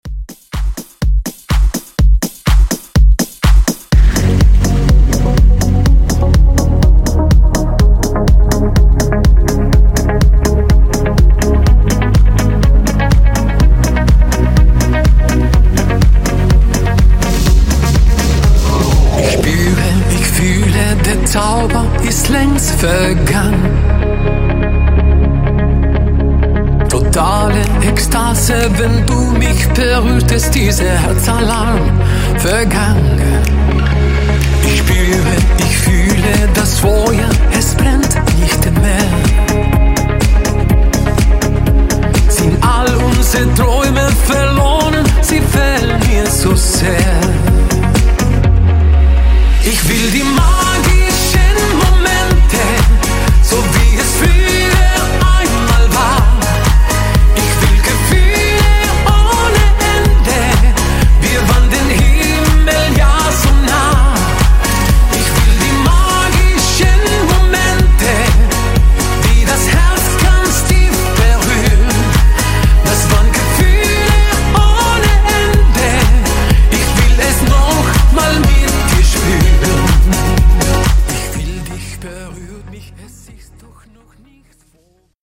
Genres: LATIN , RE-DRUM , TOP40
Clean BPM: 105 Time